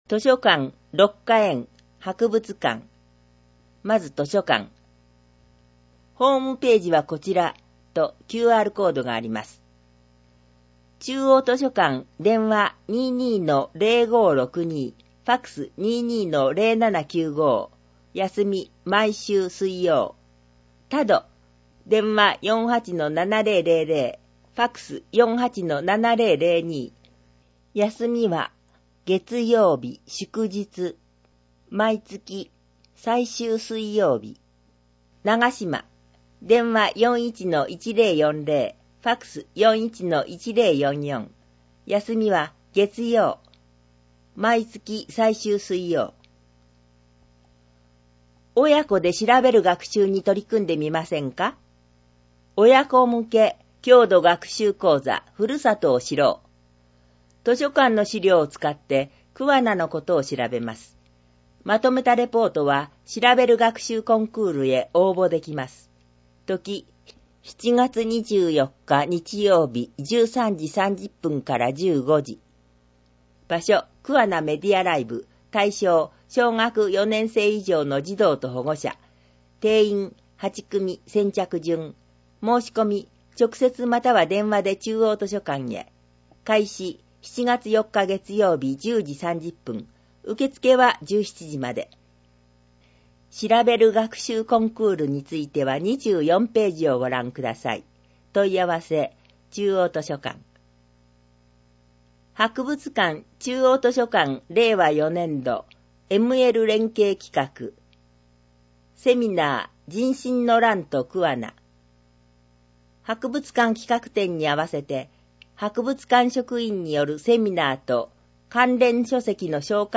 なお、「声の広報くわな」は桑名市社会福祉協議会のボランティアグループ「桑名録音奉仕の会」の協力で制作しています。